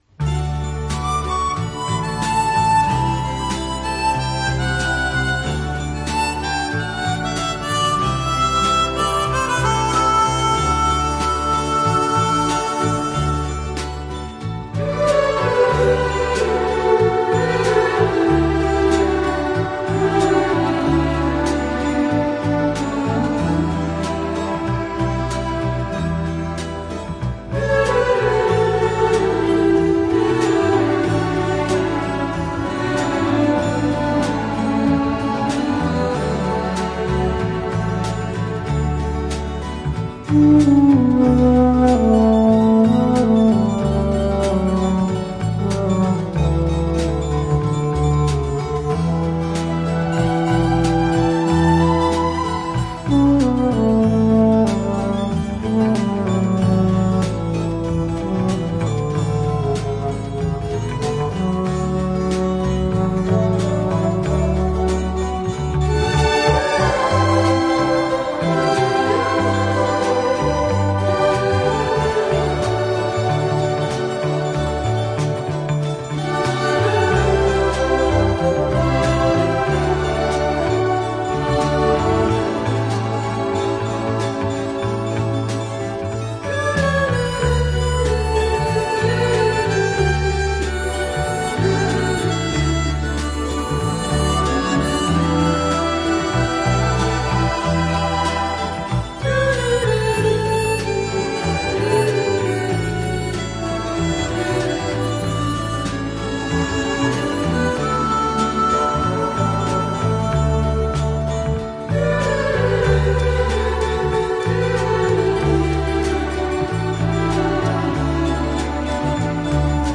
Жанр: Easy Listening